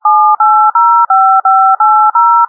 Звук нажатия клавиш стационарного телефона набирают номер